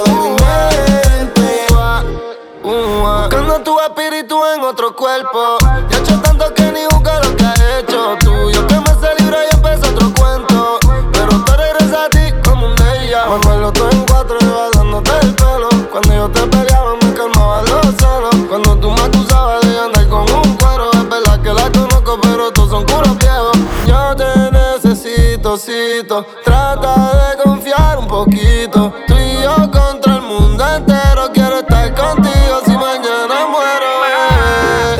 Жанр: Латиноамериканская музыка / Русские